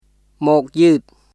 [モーク・ユート　mɔ̀ːk yɯːt]